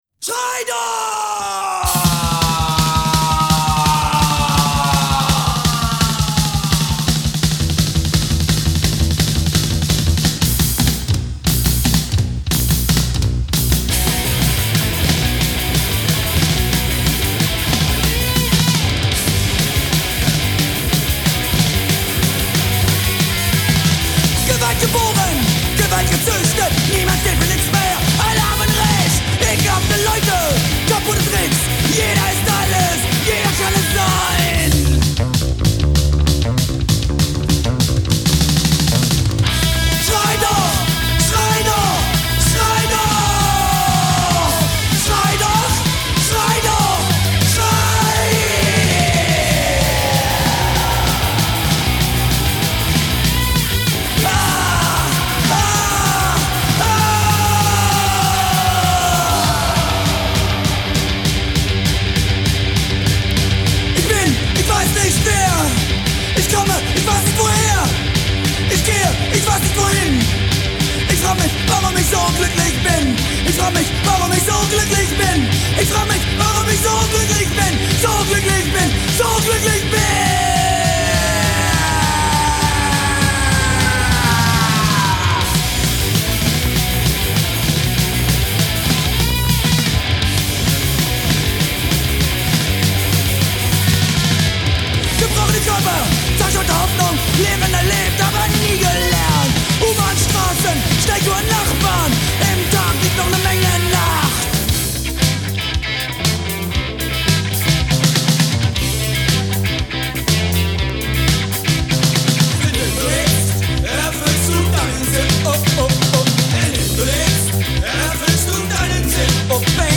Snak om punk!